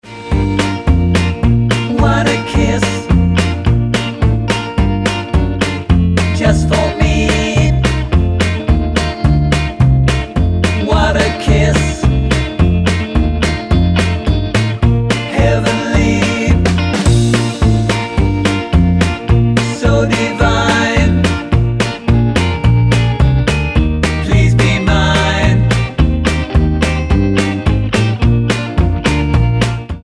Tonart:Bb-B mit Chor